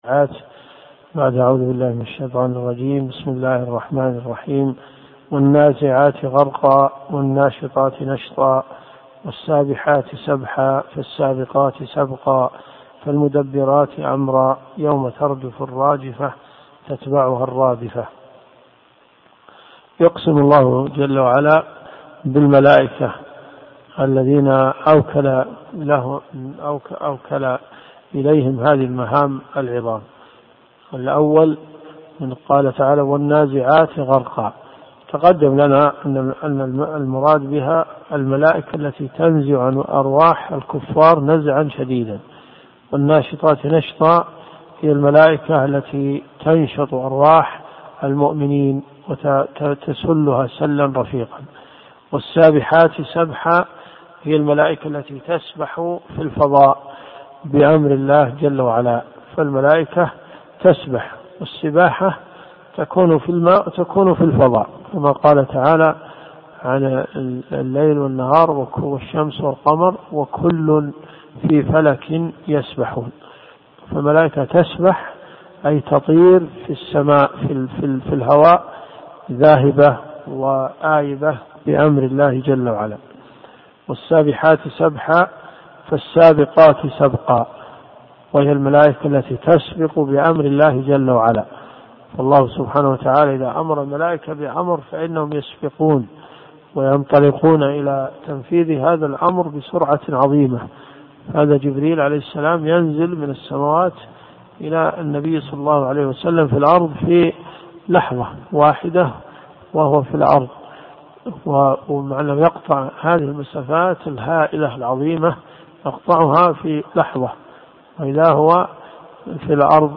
تفسير القران الكريم